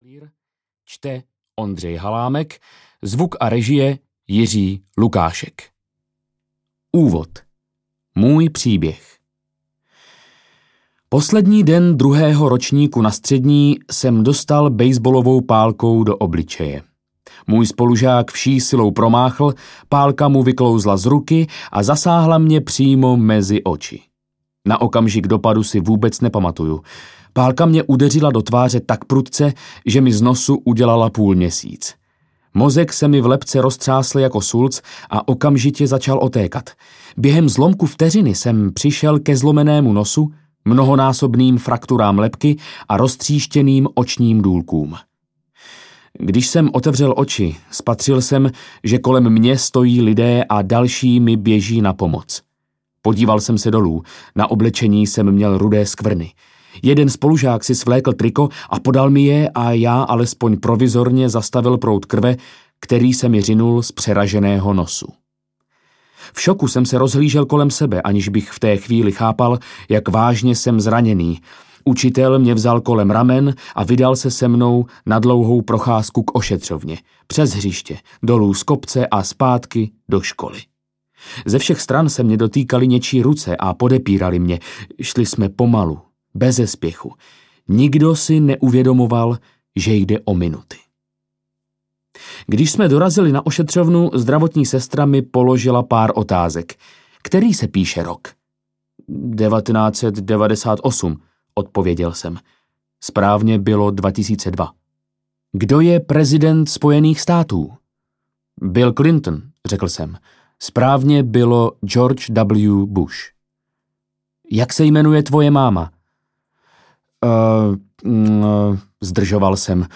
Atomové návyky audiokniha
Ukázka z knihy
atomove-navyky-audiokniha